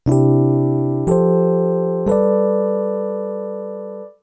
Voicing A (basic voicing): 1st, 2nd, 3rd, 5th
Here the 2nd and 3rd are next to each other adding a dissonance to what would otherwise be a plain, consonant major chord. In most cases, there will be a gap of just over an octave between the bass note and the lowest note of the triad.
G mu major